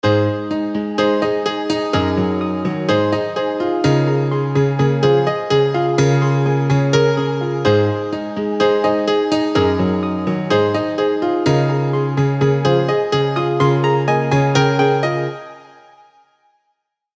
Download Piano sound effect for free.
Piano